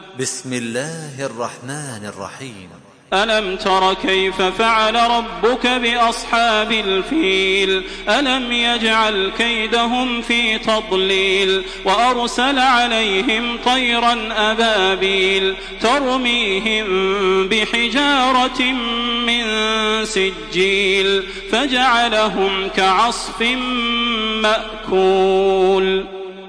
Surah Fil MP3 by Makkah Taraweeh 1427 in Hafs An Asim narration.